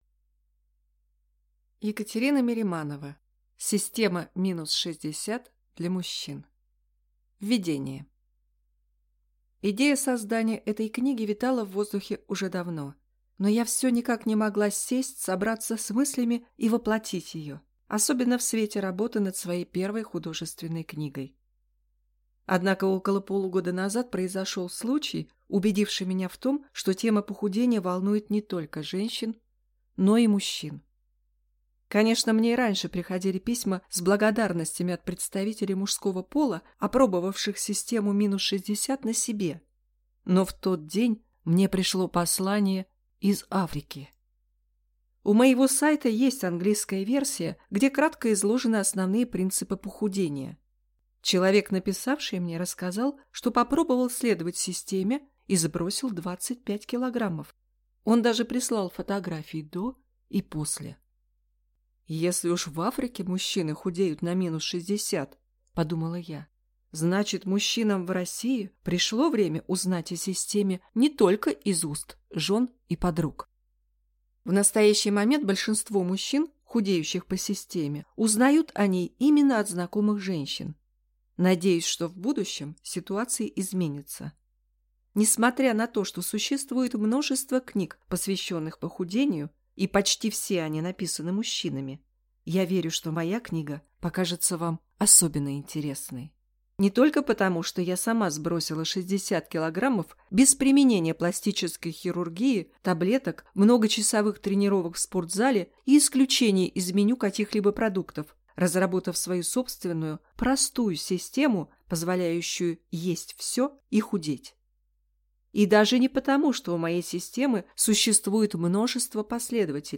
Аудиокнига Система минус 60 для мужчин | Библиотека аудиокниг